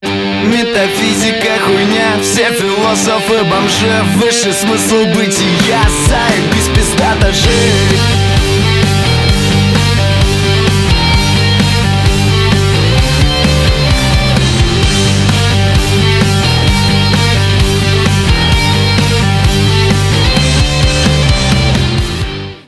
Рок Металл
громкие